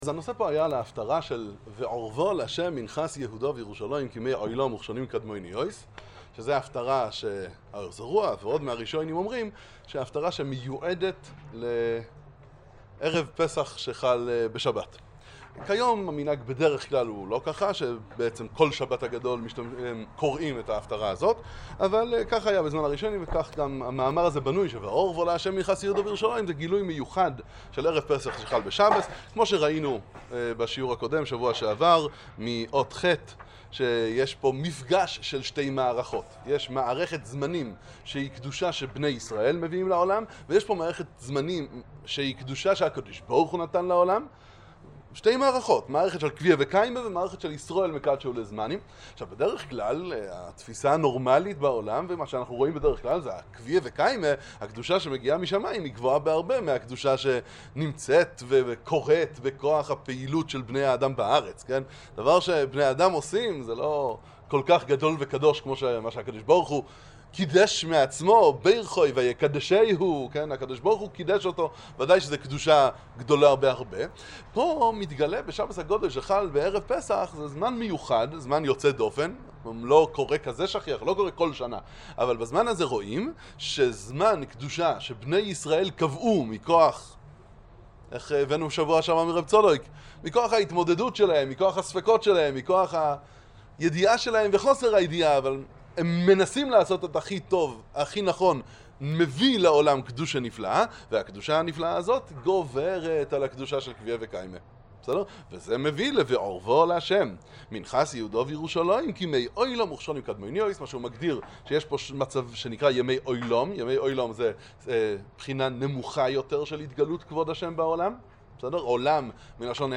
שיעור הכנה לפסח